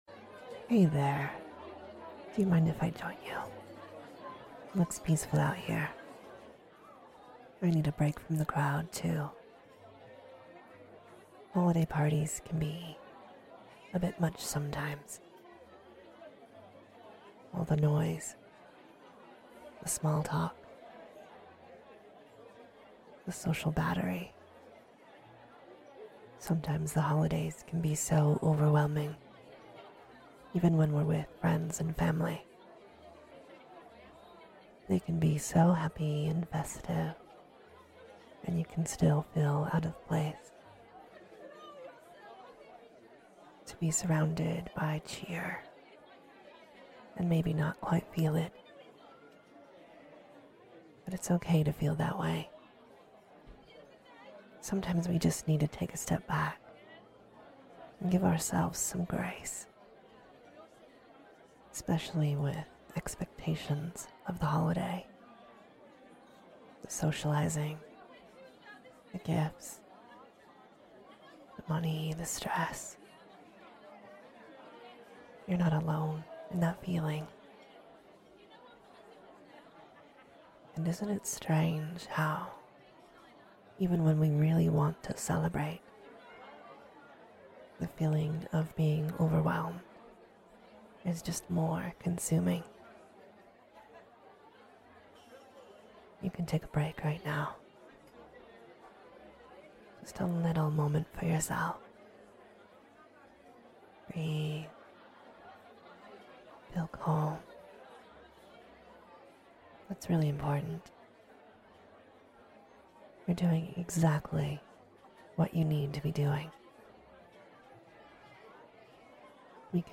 Let me hang out with you for a moment while we relax in a bustling party atmosphere.